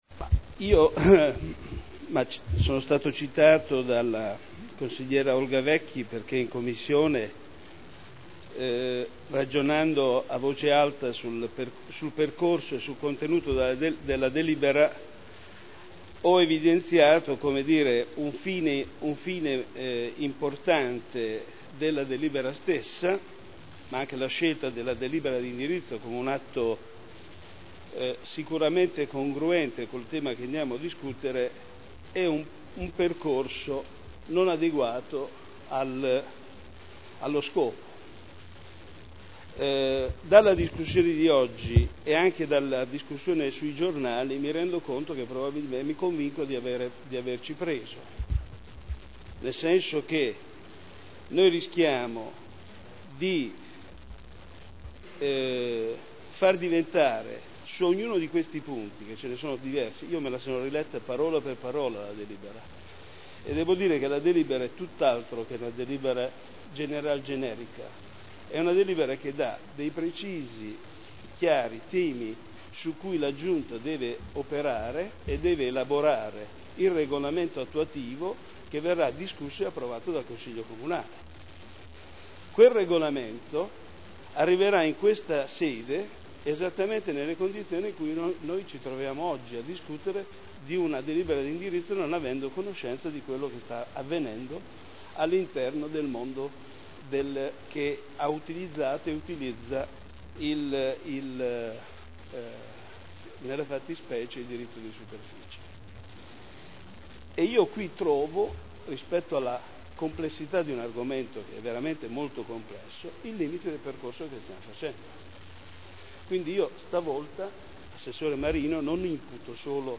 Seduta del 13/12/2010 Deliberazione: Approvazione degli indirizzi per la concessione in diritto di superficie di aree comunali Dibattito